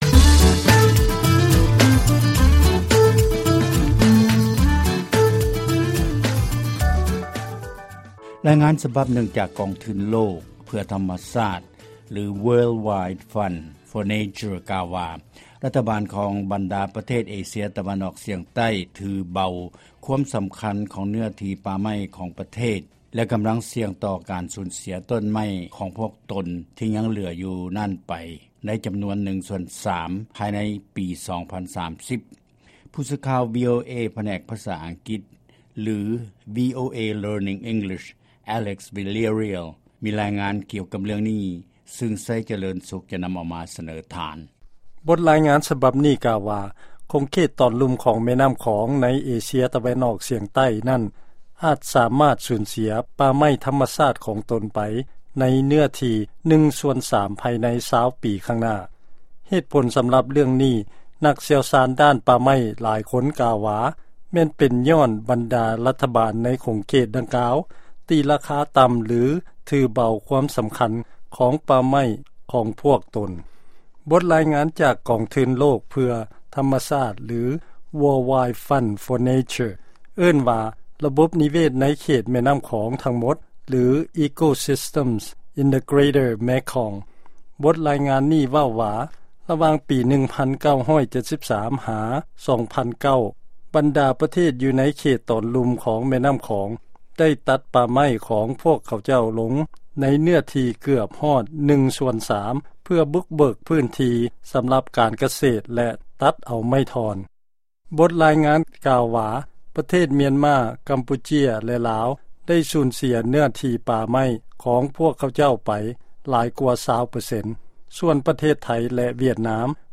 ຟັງລາຍງານ ບັນດາປະເທດ ເອເຊຍຕາເວັນອອກສຽງໃຕ້ ຖືເບົາຄວາມສຳຄັນ ຂອງເນື້ອທີ່ປ່າໄມ້.